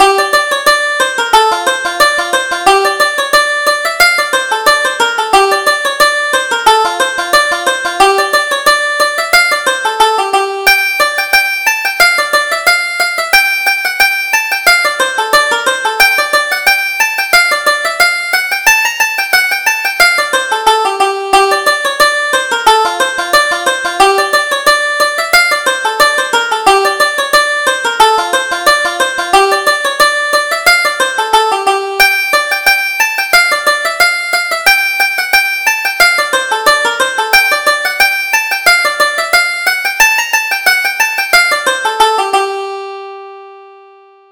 Reel: Fair Haired Mary